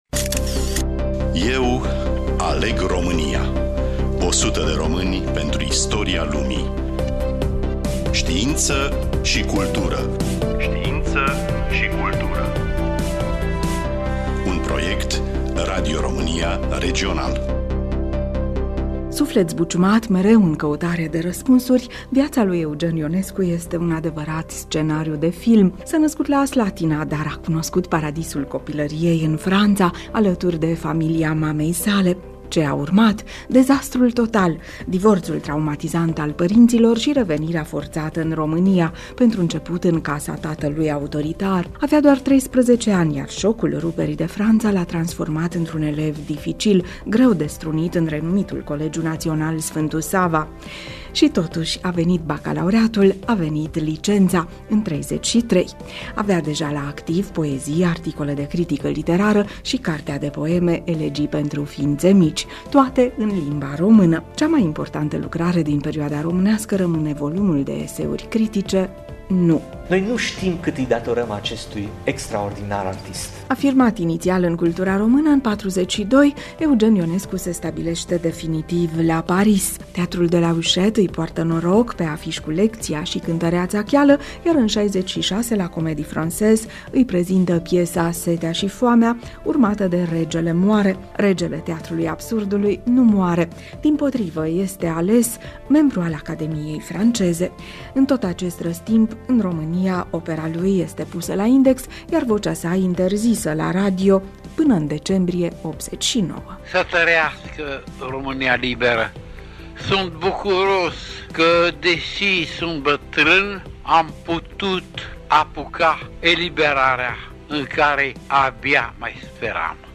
După ani şi ani de „deşert” în România, vocea lui Eugen Ionescu s-a auzit din nou în decembrie ’89,într-un Mesaj transmis de Radio Europa Liberă.
Studioul: Radio România Tg.Mureş